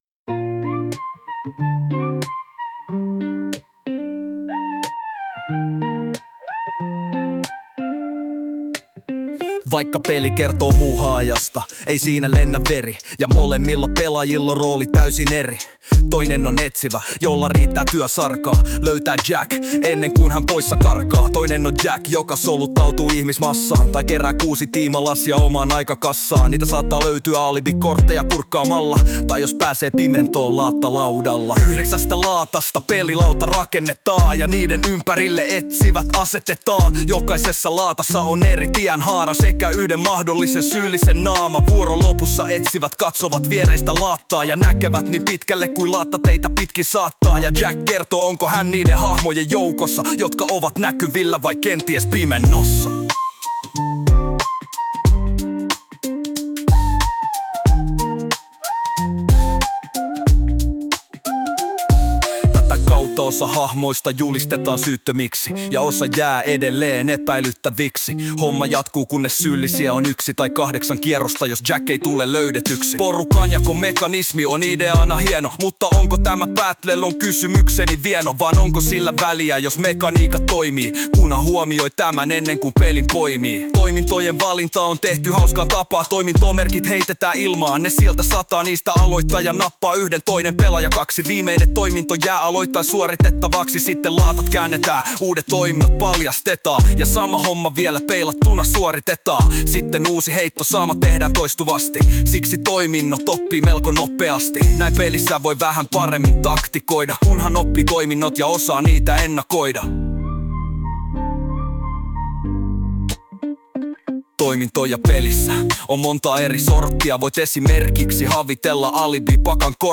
löytyy tämän alta räppi valmiiksi laulettuna.
Siinä letkeä on meno ja miellyttävä sävy,
laulun sekä musiikin hoitaa Sunon tekoäly.